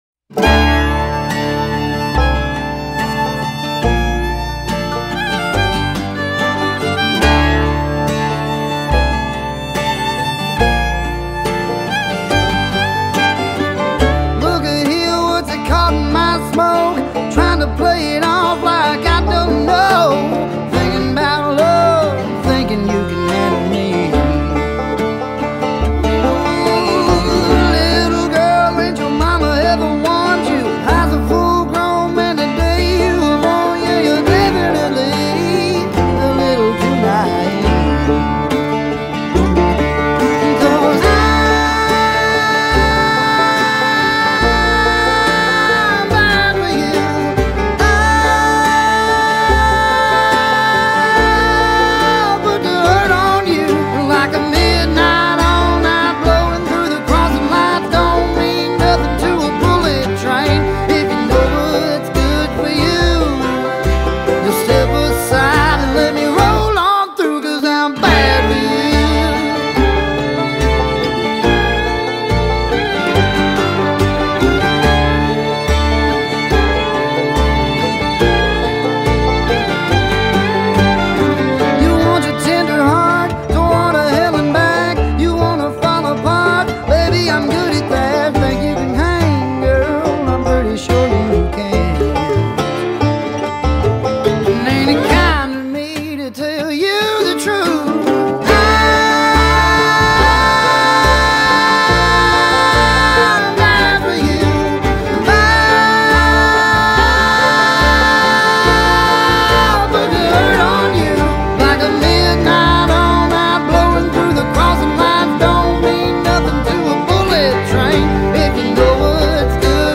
a turn towards an edgier sound than previous recordings.
recorded in Nashville, TN